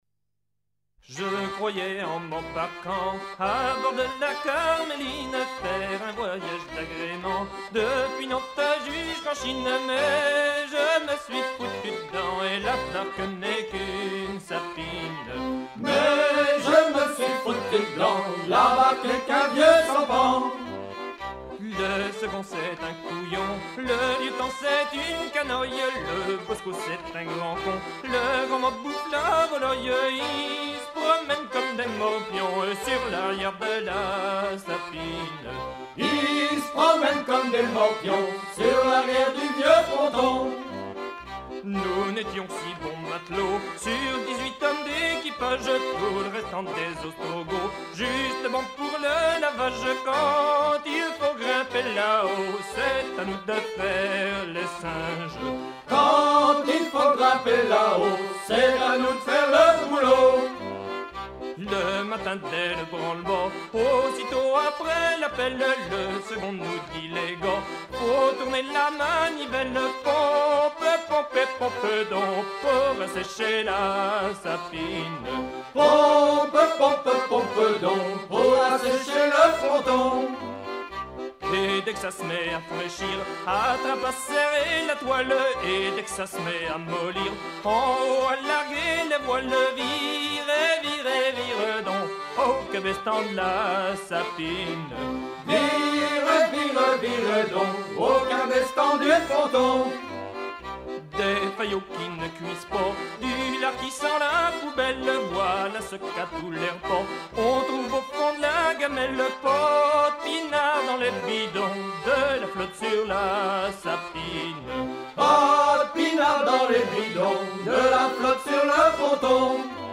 gestuel : à virer au cabestan
circonstance : maritimes
Pièce musicale éditée